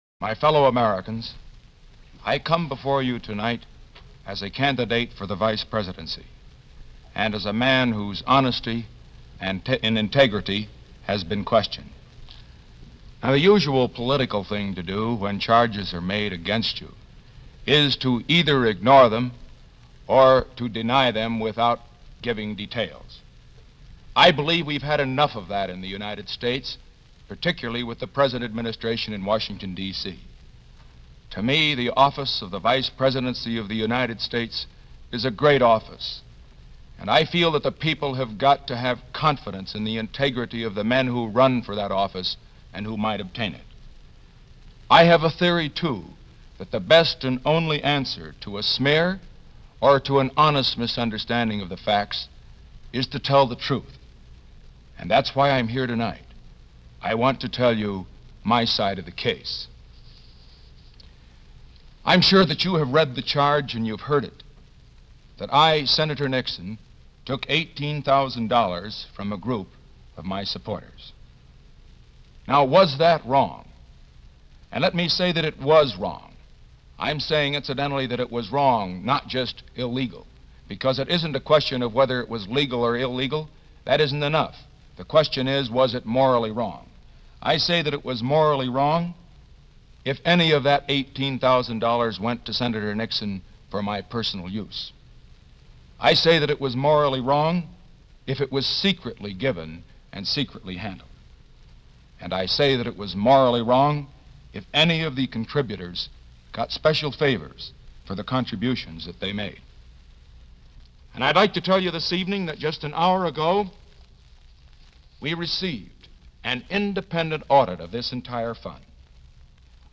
Here is that address, given to the nation on September 23, 1952 – on radio, TV and everywhere else.